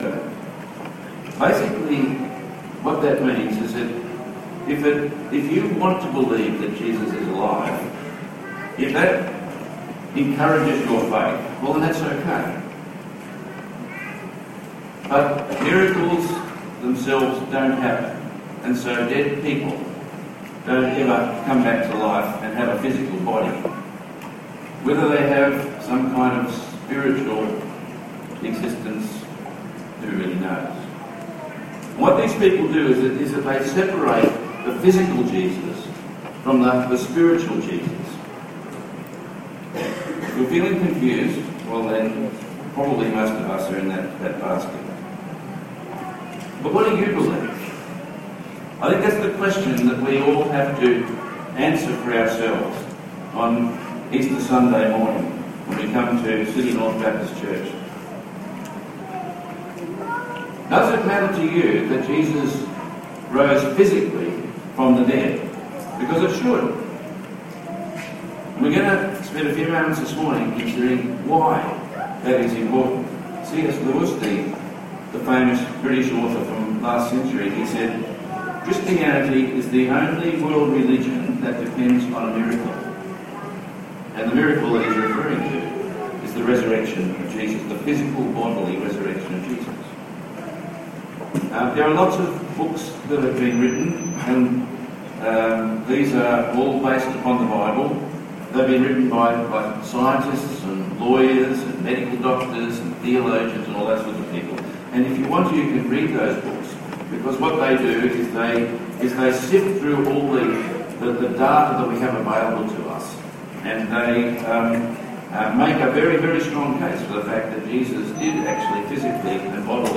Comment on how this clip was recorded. It really does matter enormously what we believe about the resurrection! 1 Corinthians 15:12-24 Tagged with Sunday Morning